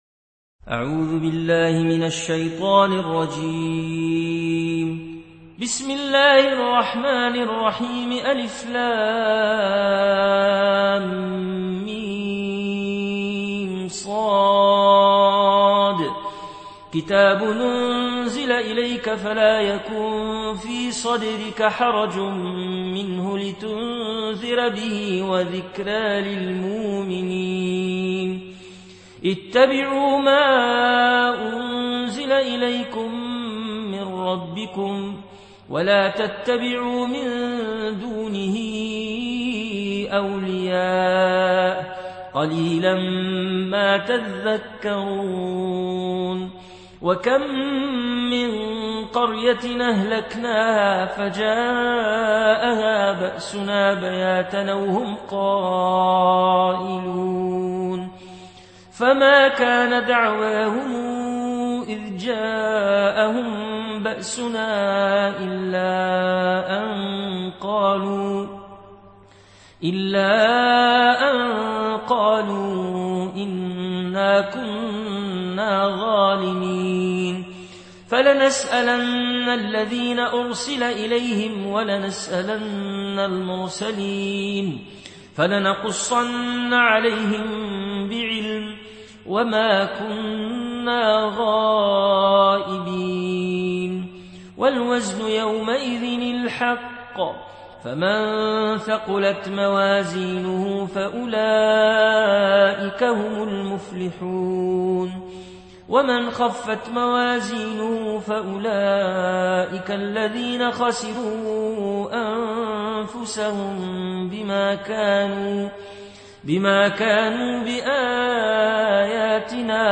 Riwayat Warsh an Nafi